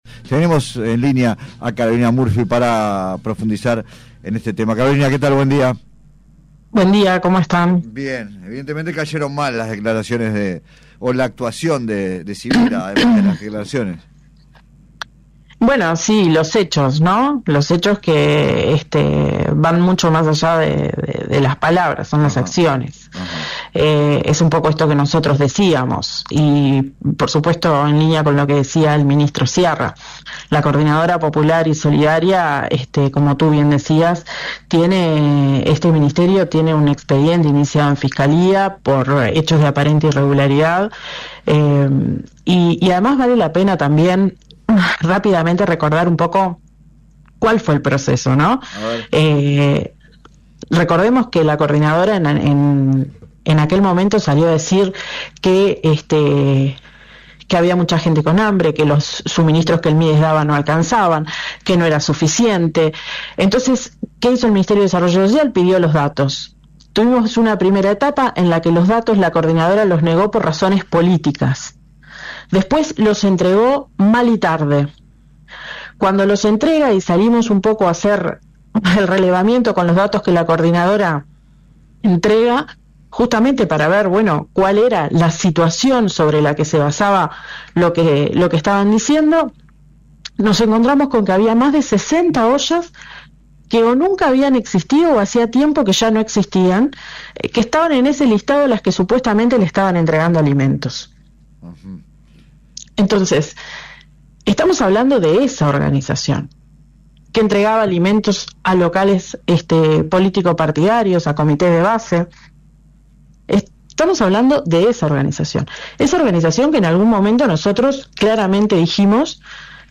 Entrevista completa en el siguiente link: